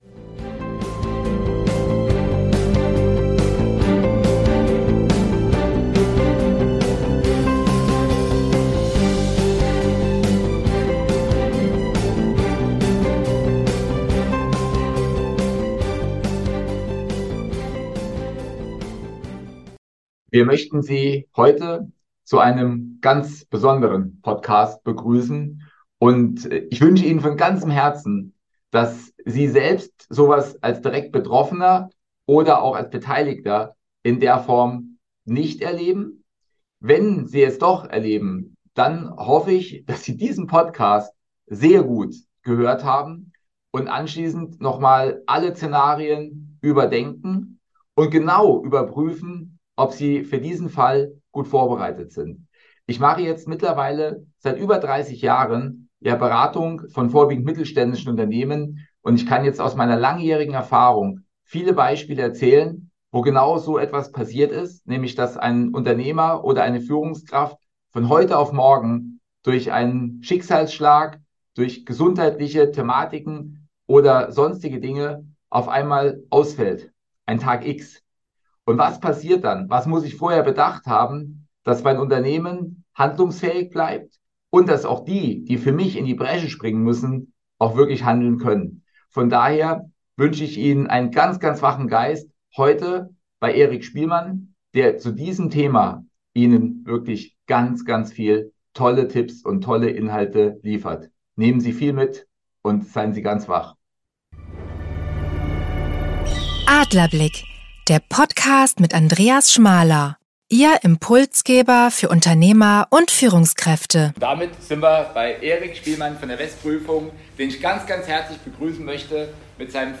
Beschreibung vor 2 Jahren Heute gibt es eine sehr besondere Podcast-Folge live von unserer CIC-Jahrestagung am 20.10.2023. Thema ist Tag X, welcher den plötzlichen Ausfall des Geschäftsführenden und somit die Handlungsunfähigkeit des Unternehmens darstellt.